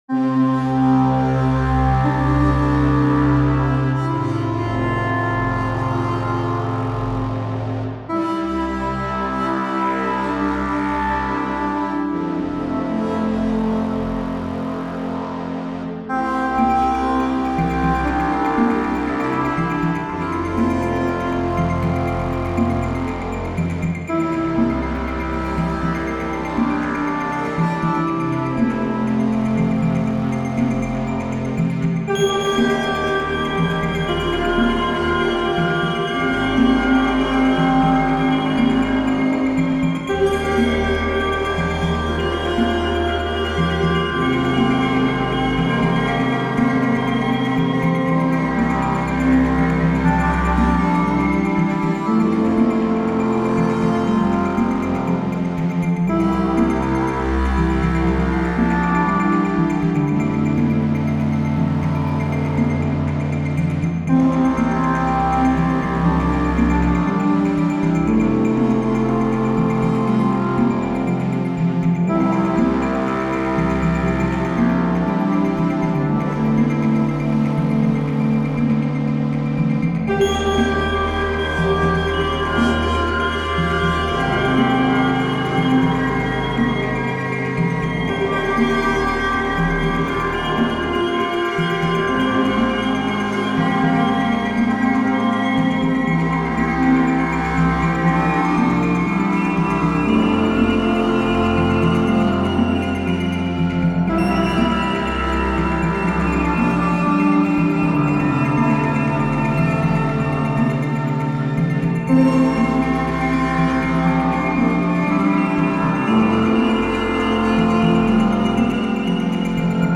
Serene oscillators twinkle on west coast mornings.